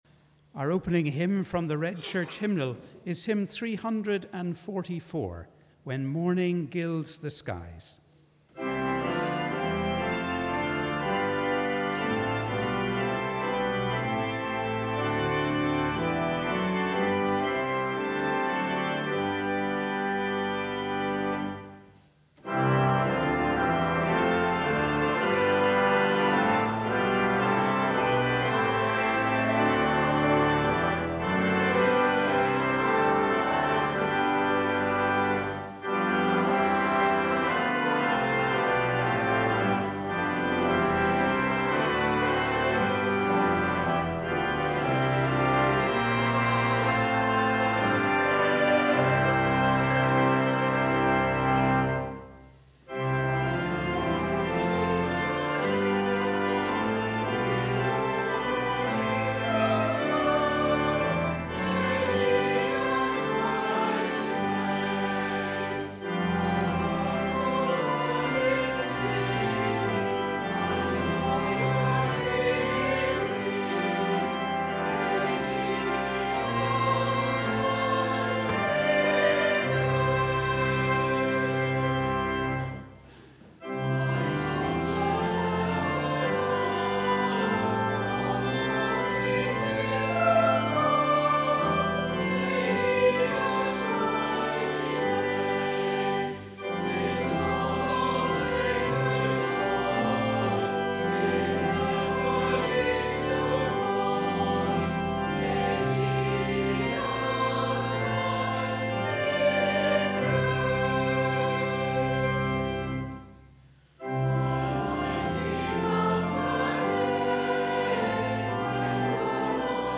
Wherever you are, we warmly welcome you to our service of Morning Prayer on the 12th Sunday after Trinity.